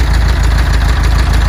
buggy_engine.ogg